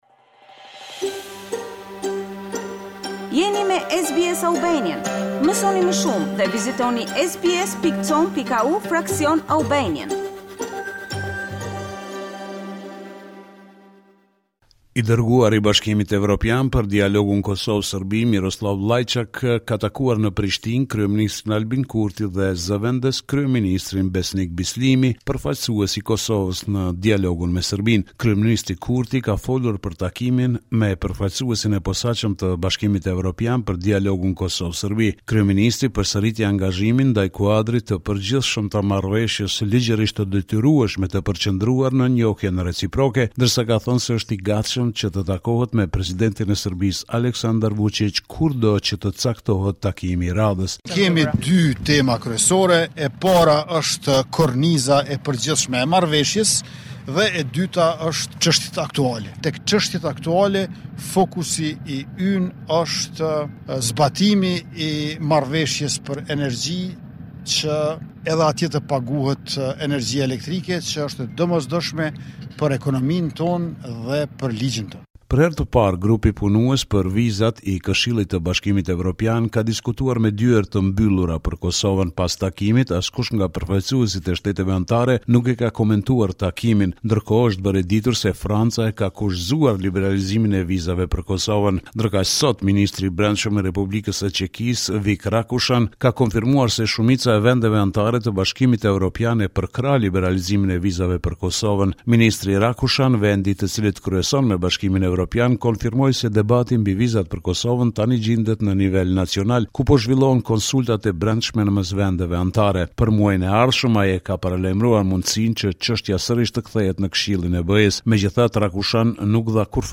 This is a report summarizing the latest developments in news and current affairs in Kosova.